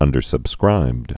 (ŭndər-səb-skrībd)